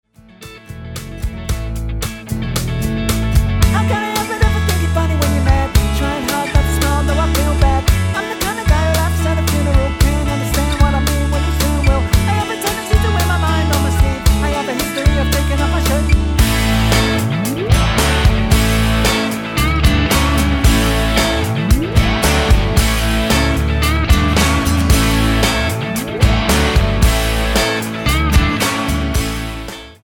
Tonart:A mit Chor